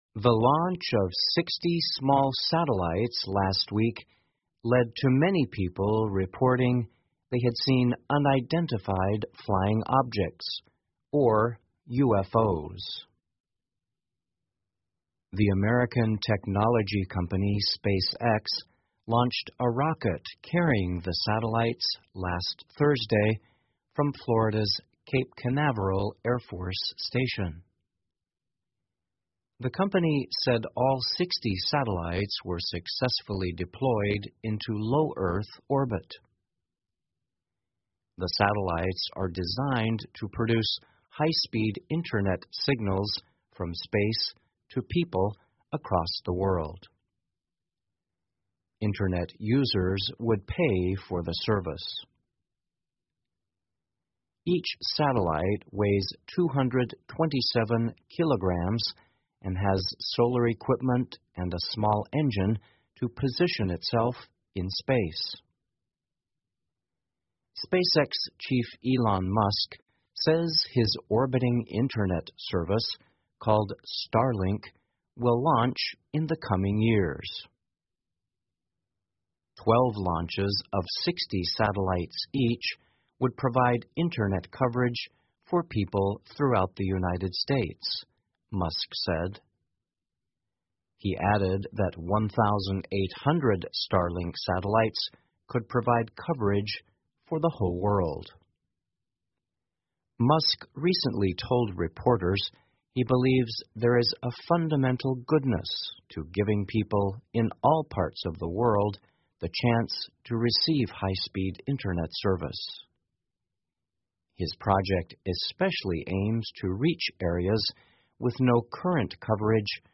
VOA慢速英语2019 卫星发射致UFO目击报告激增 听力文件下载—在线英语听力室